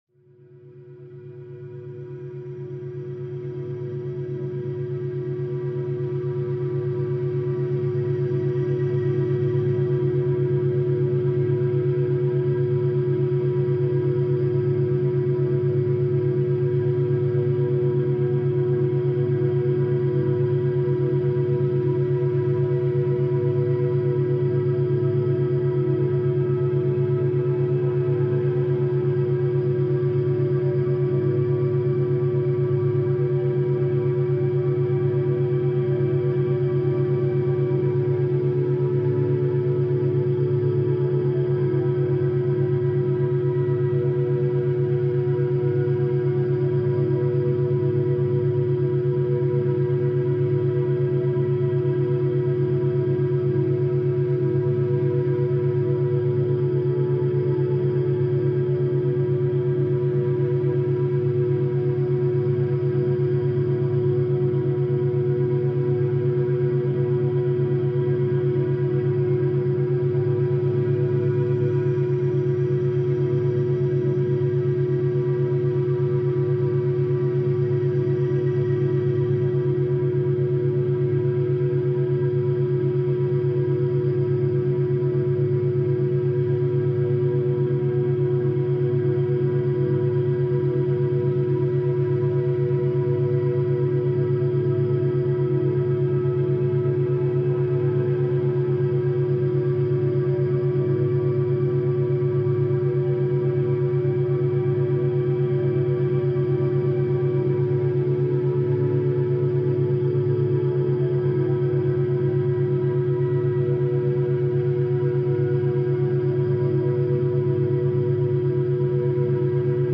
Es beginnt leise.